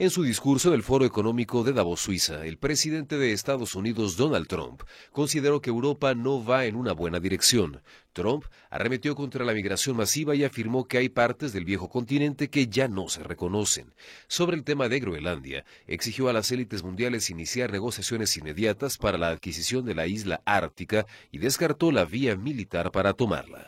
En su discurso en el Foro Económico de Davos, en Suiza, el presidente de Estados Unidos, Donald Trump, consideró que Europa no va en buena dirección. Trump arremetió contra la “migración masiva” y afirmó que hay partes del Viejo Continente que ya no se reconocen. Sobre el tema de Groenlandia, exigió a las élites mundiales iniciar “negociaciones inmediatas” para la adquisición de la isla ártica, y descartó la vía militar para tomarla.